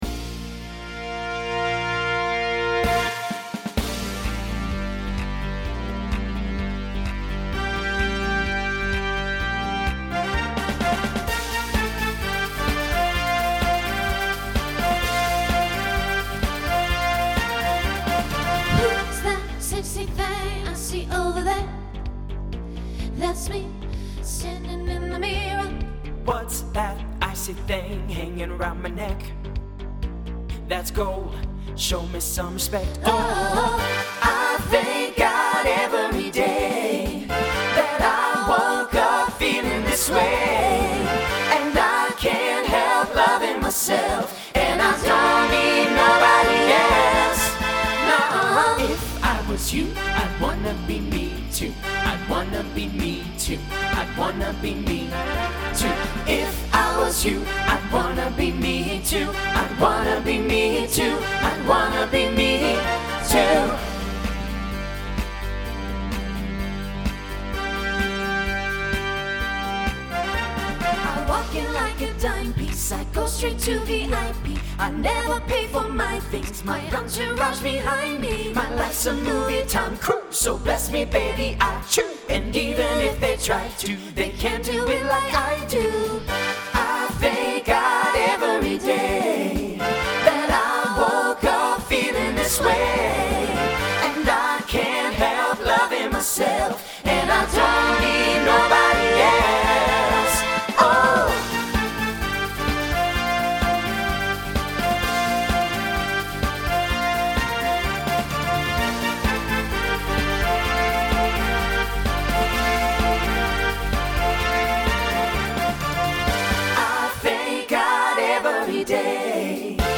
SATB SSA